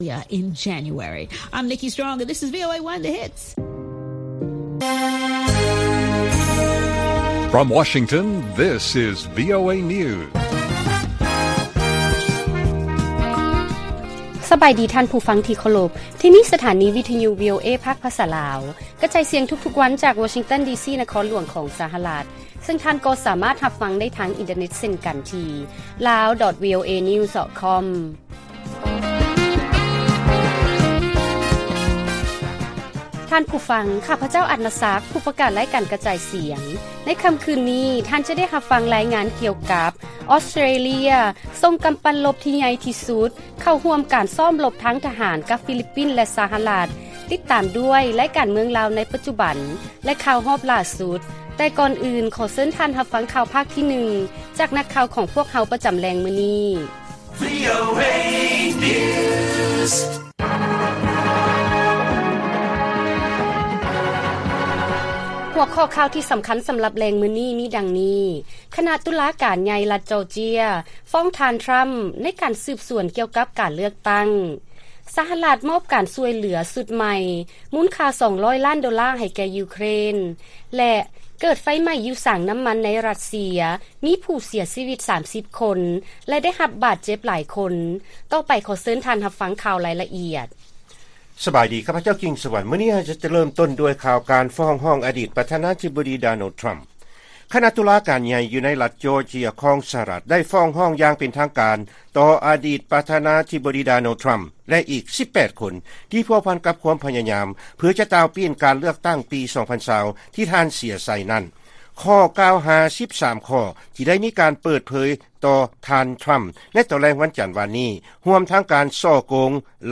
ລາຍການກະຈາຍສຽງຂອງວີໂອເອ ລາວ: ຄະນະຕຸລາການໃຫຍ່ ລັດຈໍເຈຍ ຟ້ອງທ່ານທຣໍາ ໃນການສືບສວນ ກ່ຽວກັບການເລືອກຕັ້ງ